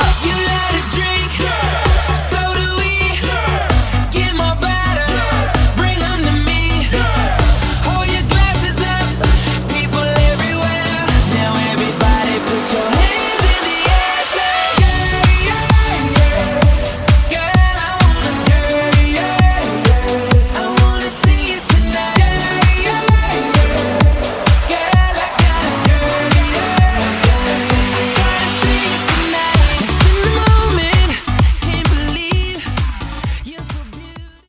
Genre: 2000's
Clean BPM: 128 Time